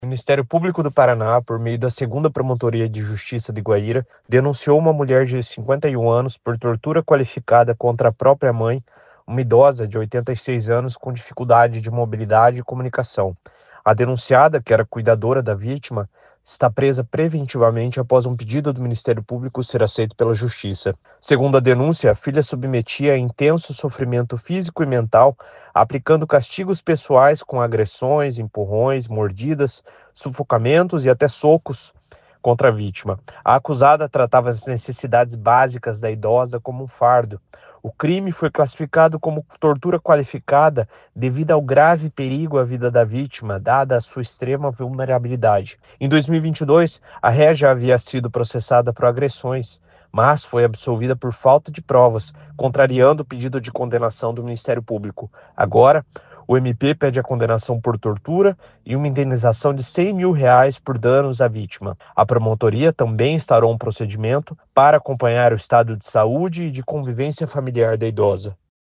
Ouça o que diz o promotor de Justiça, Renan Goes de Lima: